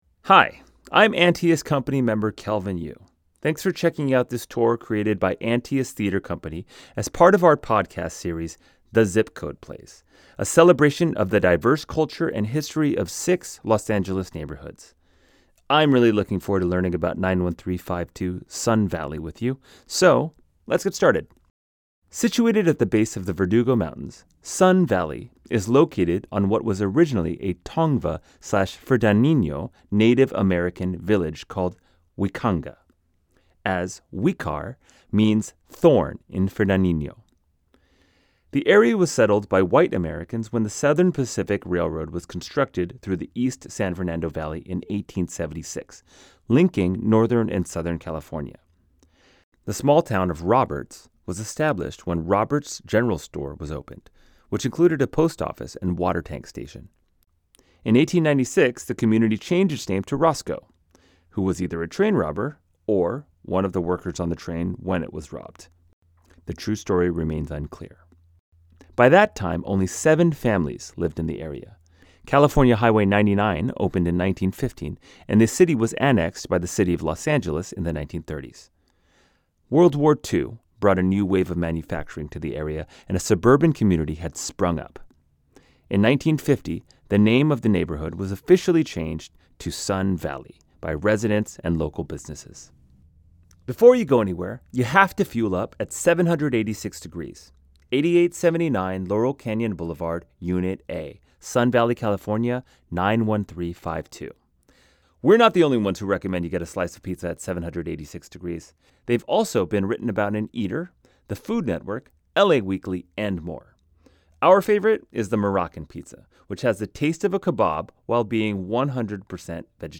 This Audio Tour is Narrated by Kelvin Yu